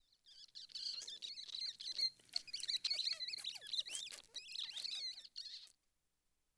Rats_Squeaks_Active.wav